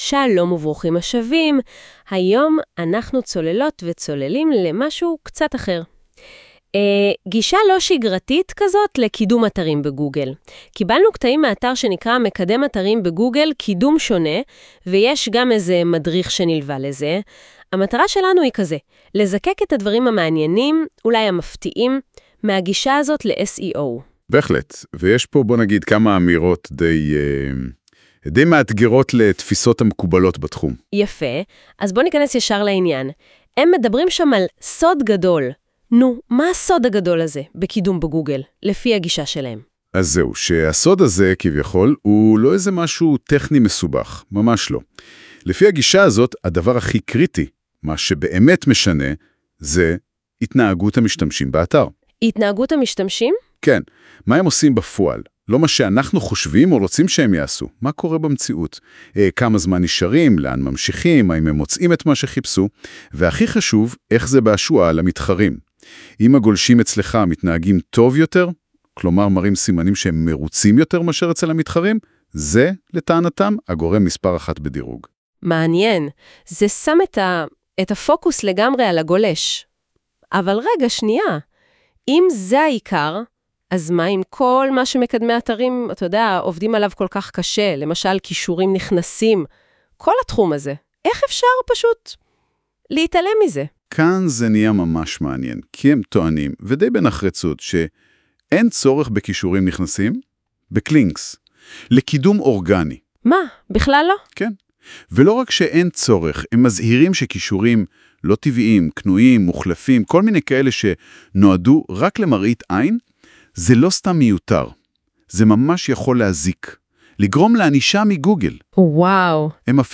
• יוצרים דו־שיח קולי שמתאים בדיוק לגולשים
מדריך קולי לקידום שונה בגוגל